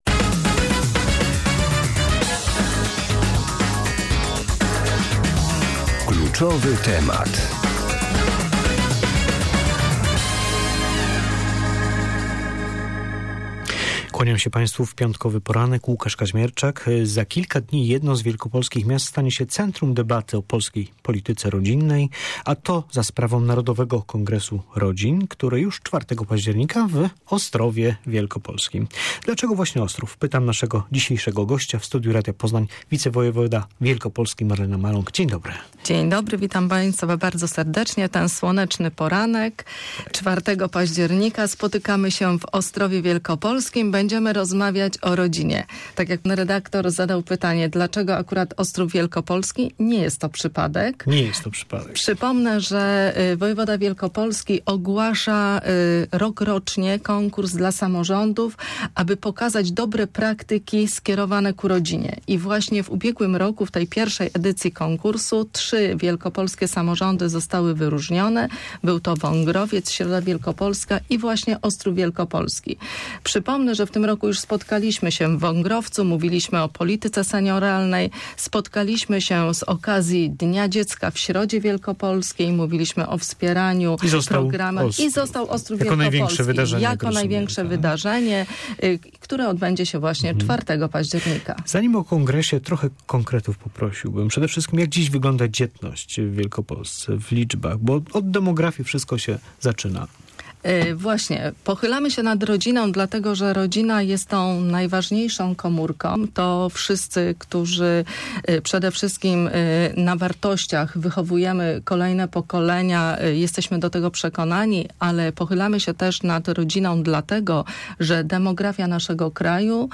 Wicewojewoda wielkopolski Marlena Maląg mówiła dziś w Radiu Poznań, że jest to pilna potrzeba - statystyki nadal wskazują na zapaść demograficzną w naszym kraju.